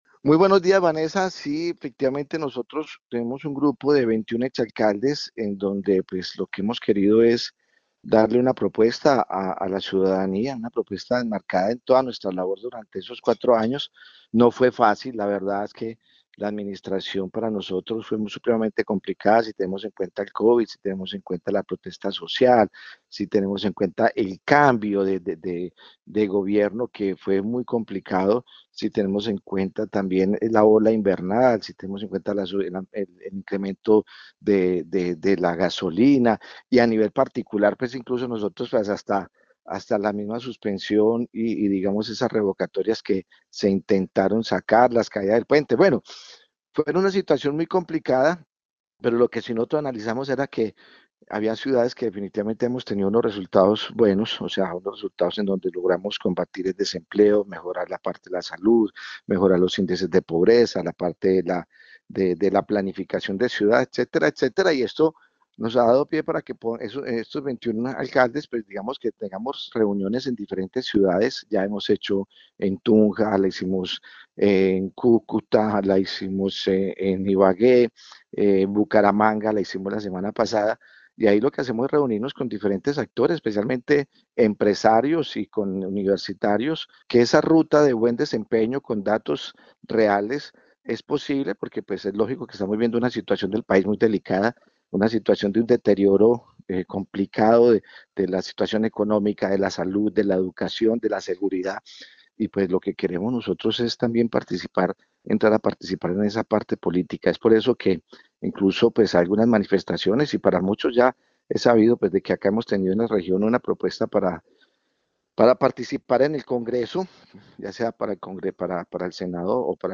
En diálogo con Caracol Radio, el exalcalde de la ciudad José Manuel Ríos explicó que lo que han querido es brindar una propuesta enmarcada en la labor durante los cuatro años entendiendo que fue muy compleja teniendo en cuenta las protestas sociales y la pandemia.